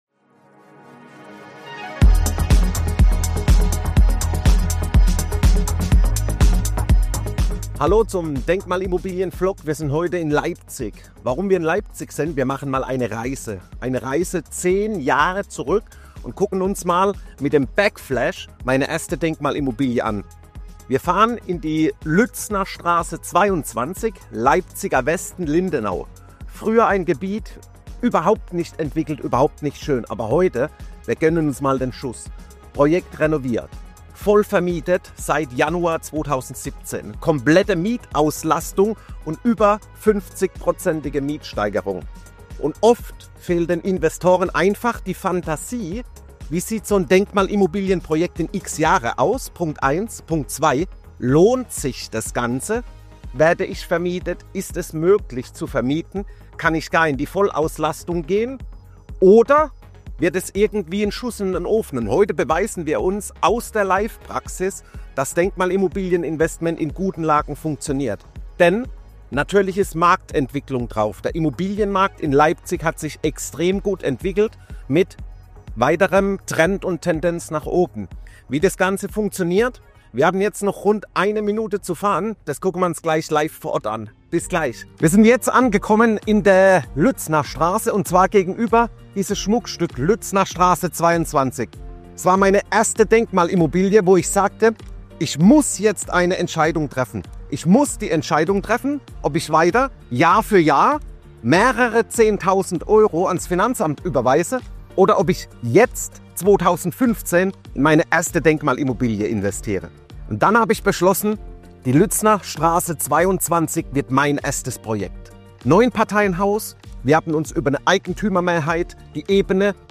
zeige ich dir live vor Ort in Leipzig, wie sich meine erste